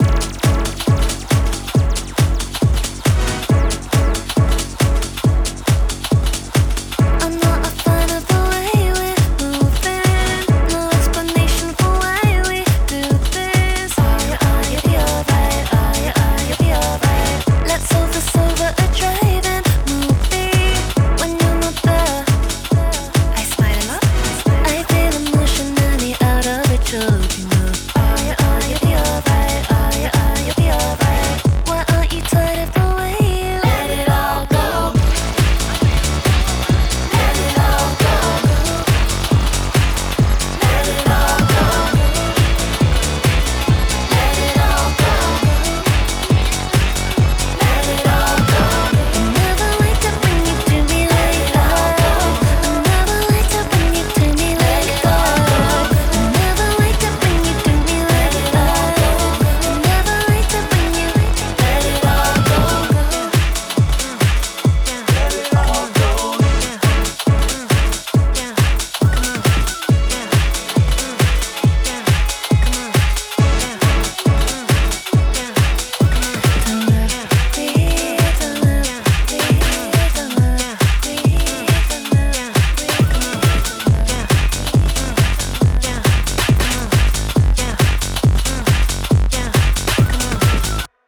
BPM138
Audio QualityPerfect (High Quality)
Genre: UK House-Pop